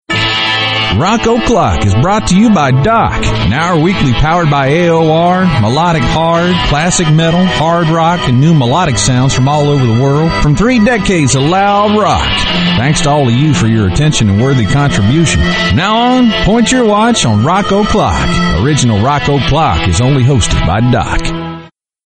RADIO IMAGING / ROCK ALTERNATIVE /